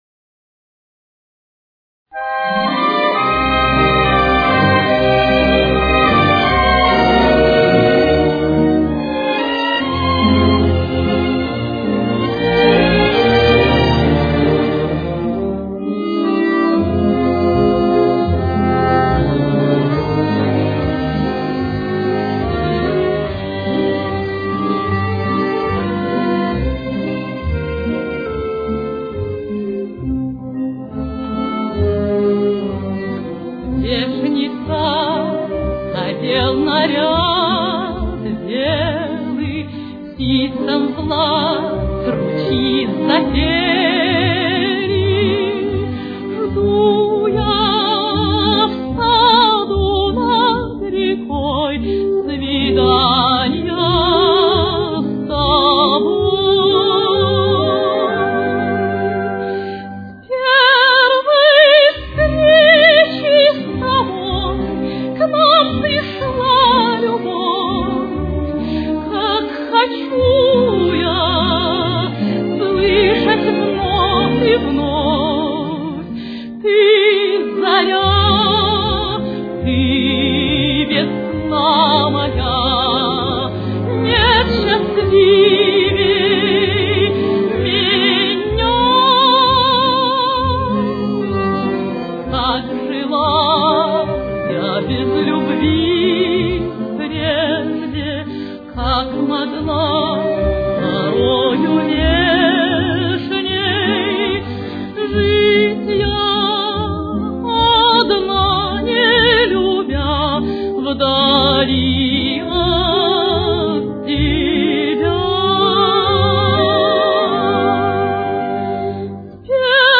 Темп: 147.